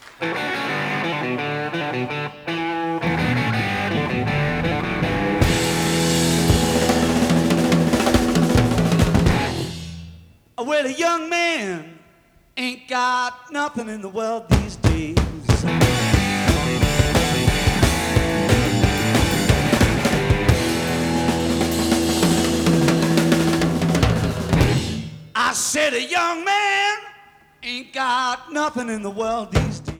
Sound Sample(s) (All Tracks In Stereo)
2014 remix HD Download version